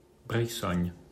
Brissogne (French: [bʁisɔɲ]
Fr-Brissogne.mp3